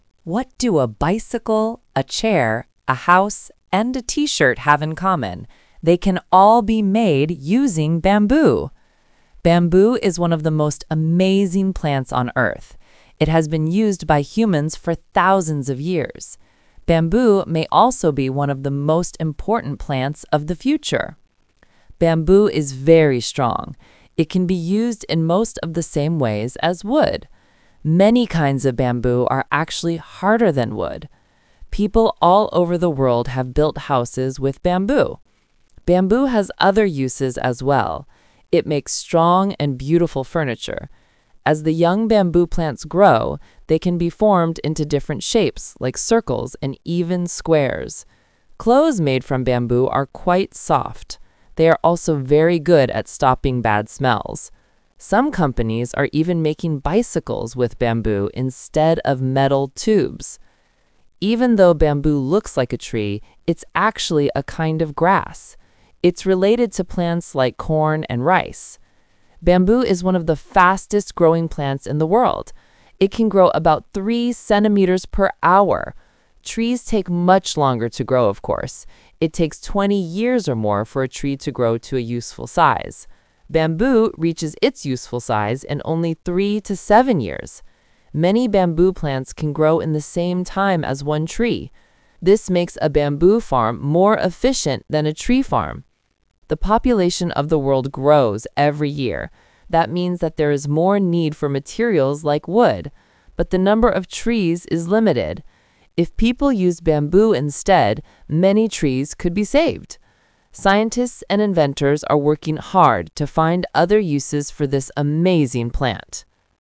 Talk/Lecture 2: Listen to a woman talking about bamboo.